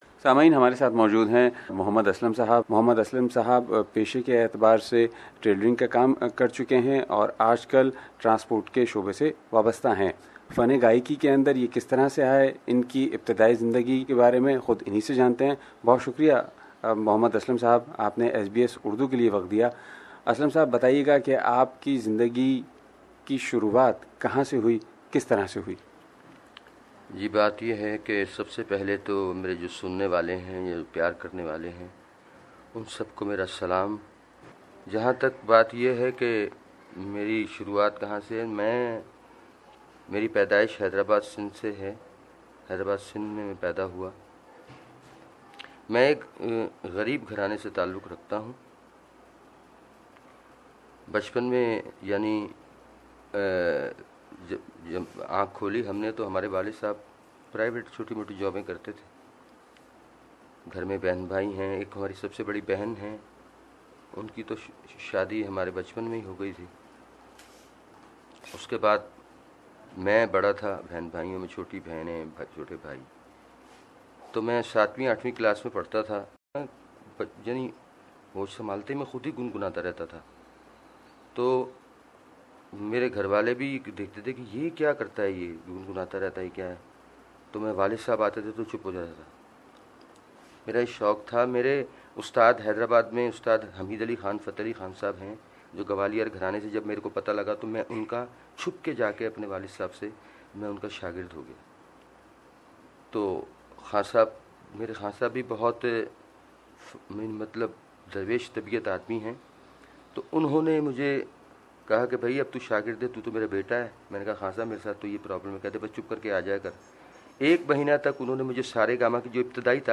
A Rickshaw Driver with melodious voice